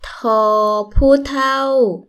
– toor ∨ puu ∧ tau
toor-puu-tau.mp3